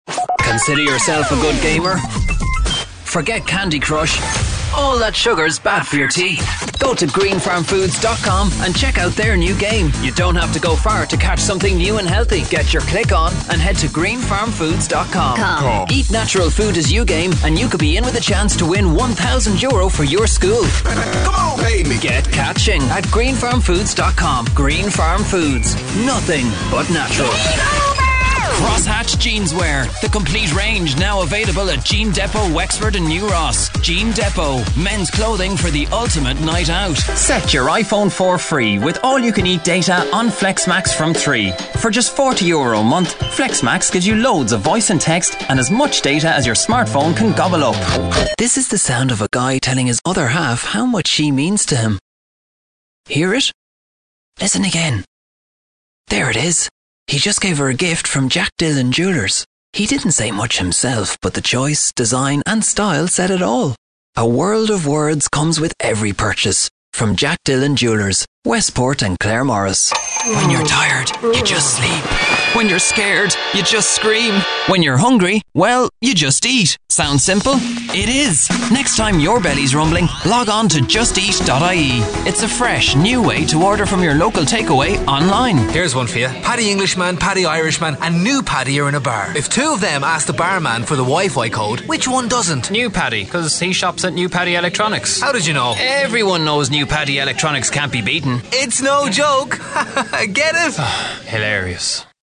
Male Irish Voiceover for TV, Radio, Online and more. A voice from friendly and natural, to bright and upbeat - helping you get heard!
irisch